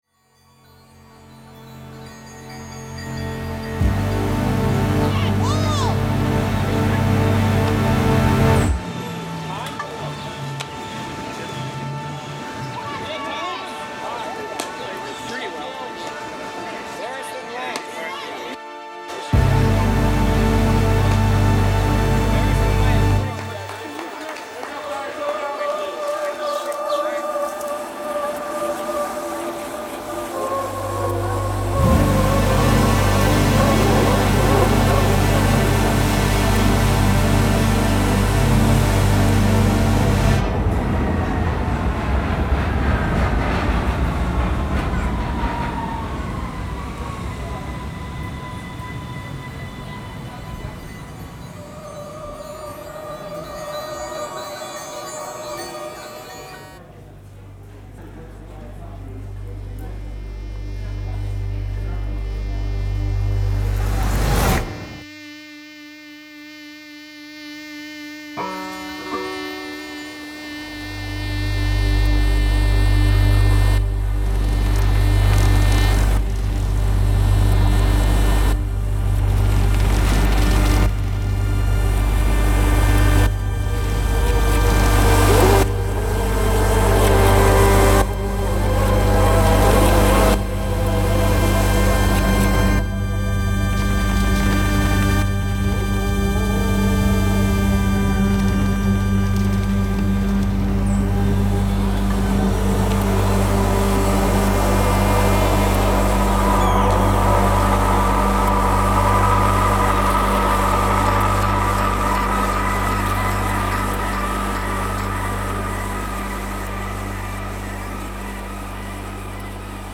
stereo version of the multichannel sound installation
Sound Art